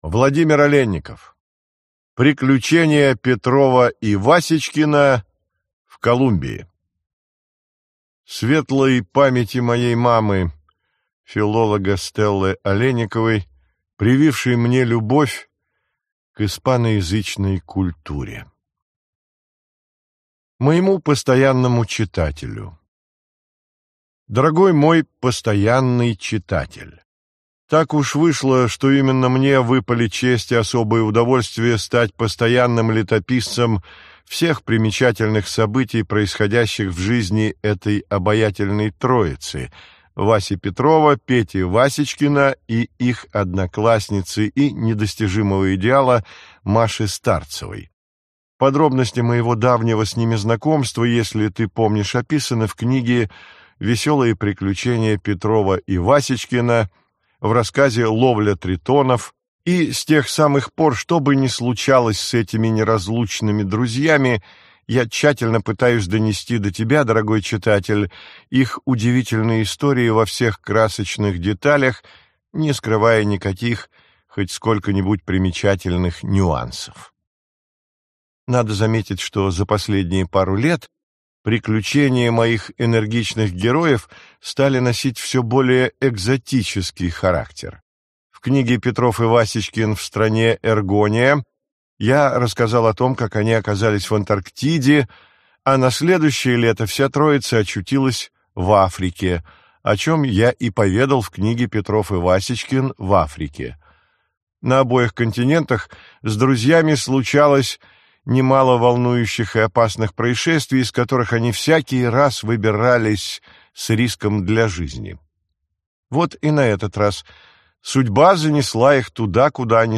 Аудиокнига Приключения Петрова и Васечкина в Колумбии. В поисках сокровищ | Библиотека аудиокниг